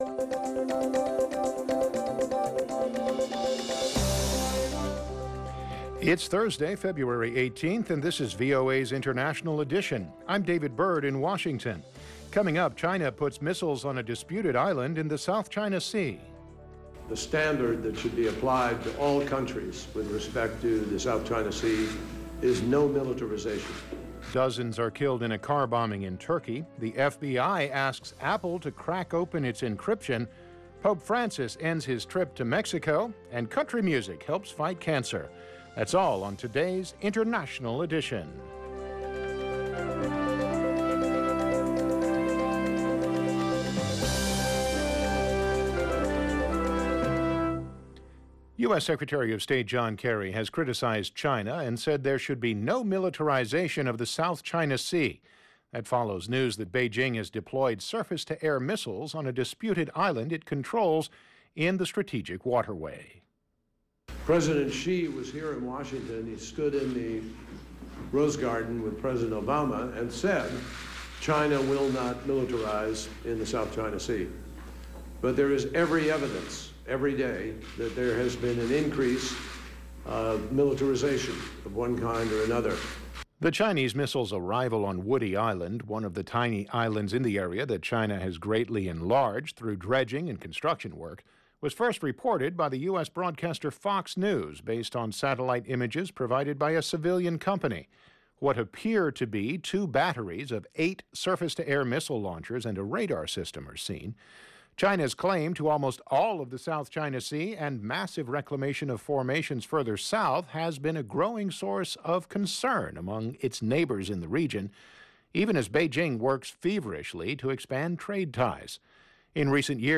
Coming up on International Edition LIVE at 0330 UTC. Dozens dead in a Turkey car bombing.